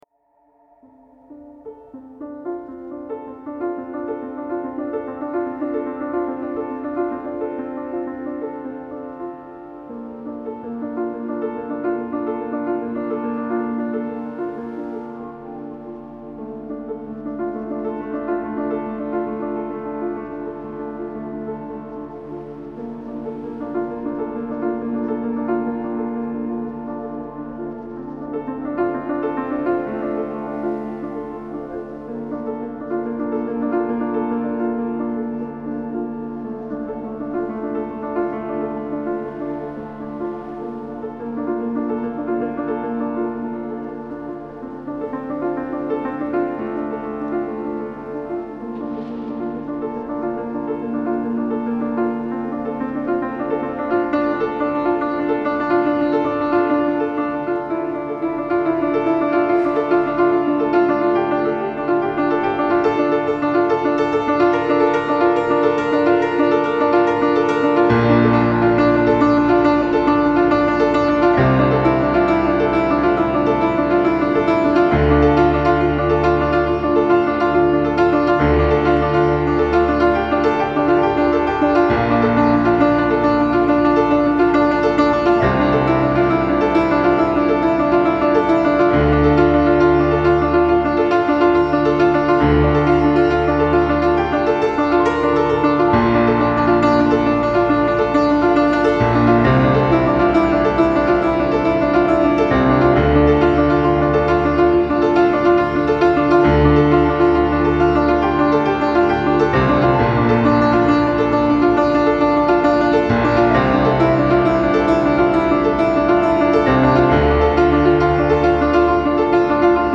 Classical Crossover
پیانو , عمیق و تامل برانگیز